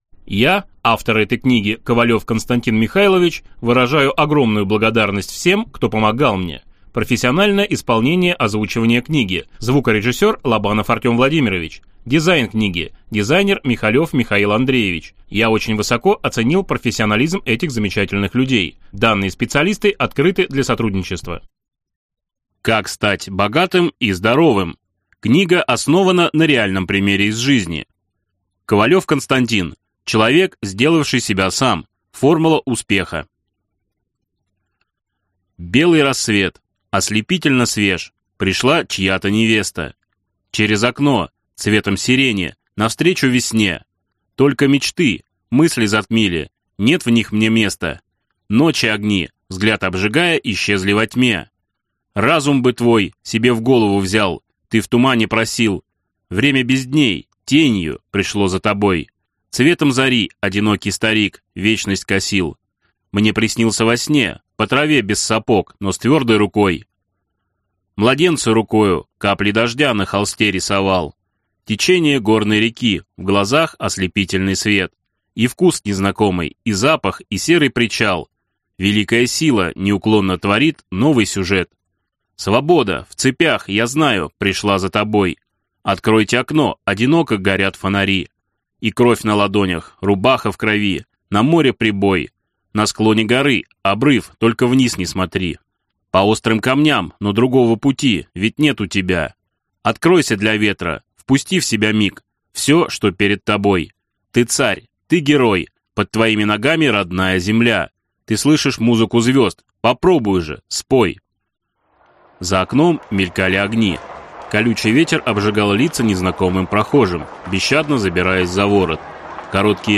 Аудиокнига Как стать богатым и здоровым | Библиотека аудиокниг